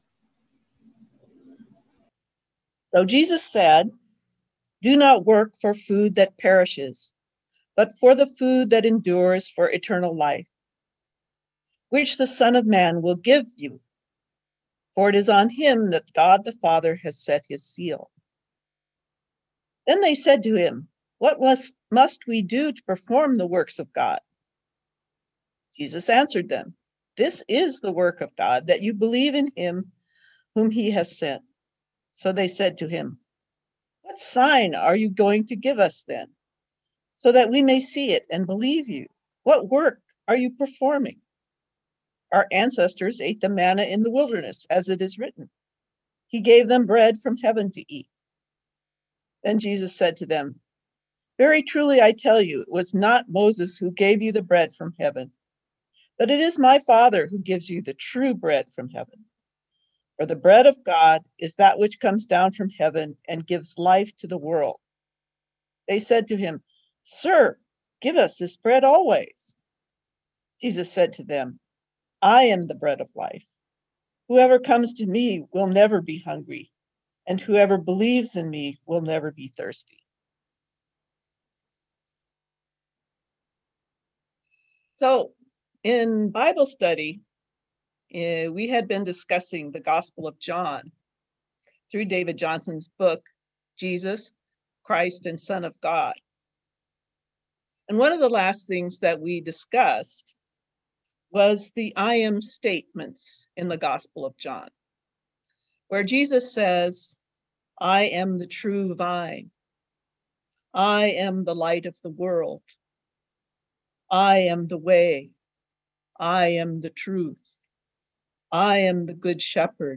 Listen to the most recent message from Sunday worship at Berkeley Friends Church, “I am the Bread of Life.”